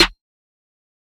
Chop Snare.wav